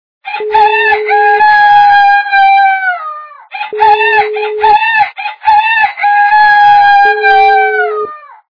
» Звуки » Природа животные » Звук - Петух
При прослушивании Звук - Петух качество понижено и присутствуют гудки.
Звук Звук - Петух